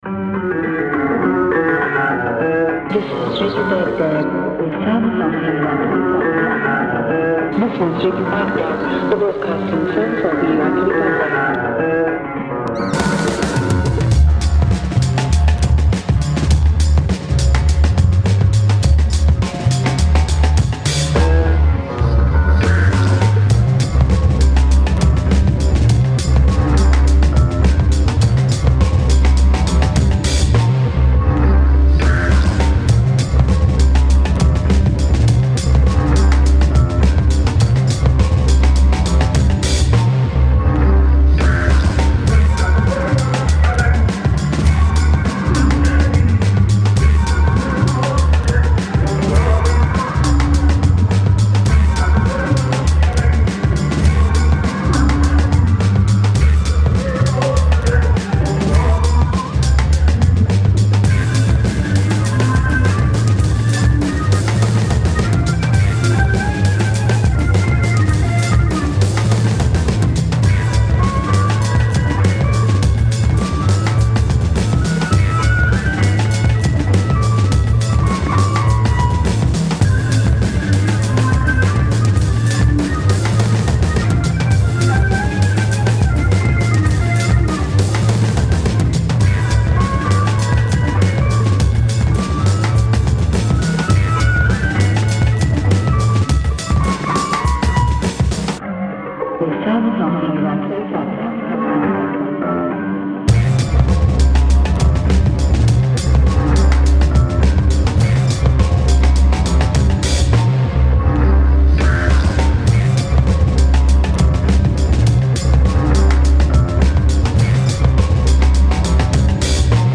I pulled drum breaks and some meaty, fuzz bass to thicken up the track.